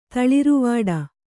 ♪ taḷiruvāḍa